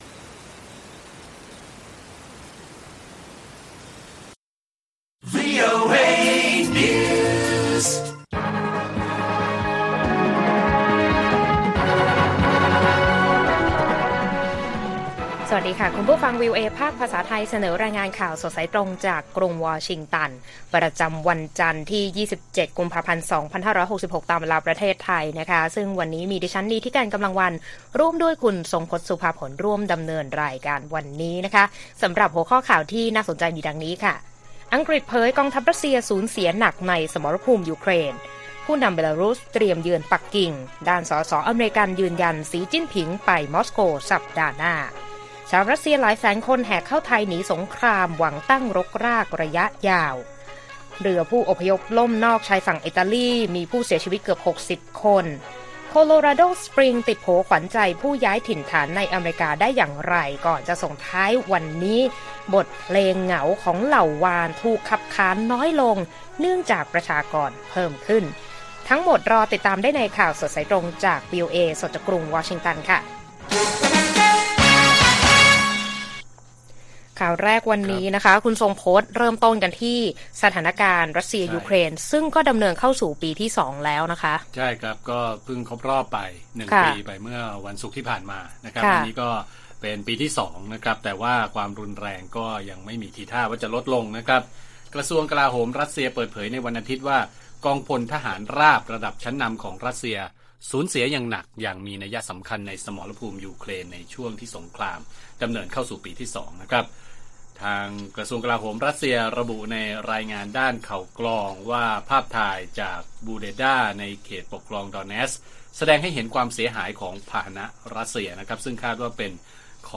ข่าวสดสายตรงจากวีโอเอไทย 6:30 – 7:00 น. 27 ก.พ. 2566